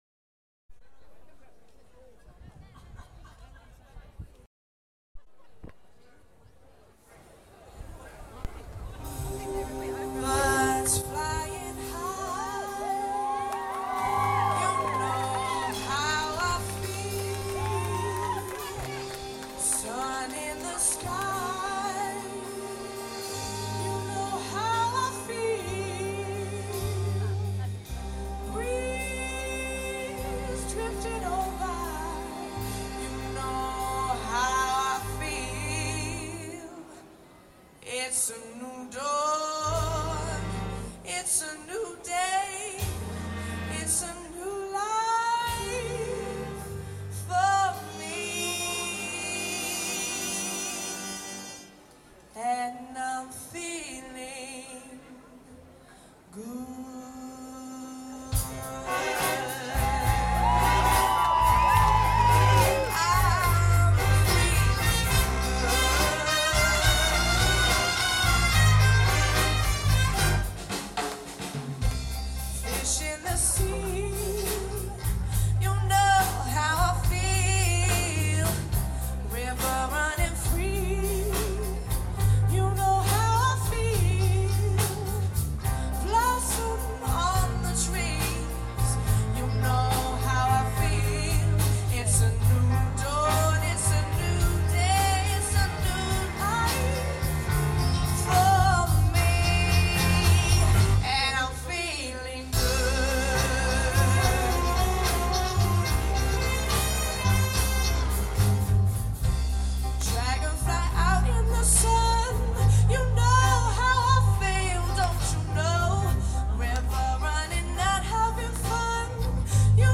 Big Band @ Ghent